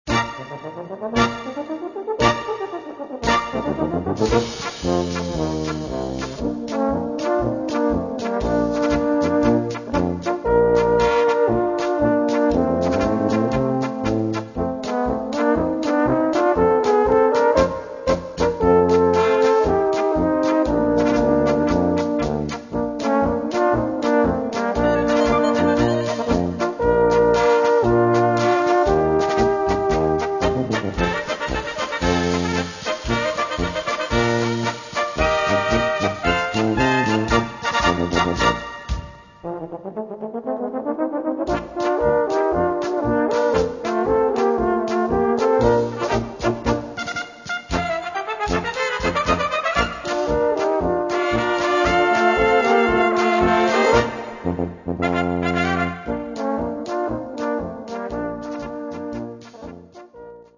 Gattung: Solo für Tenorhorn und großes Blasorchester
Besetzung: Blasorchester